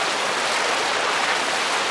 KART_Skidding_On_Grass.ogg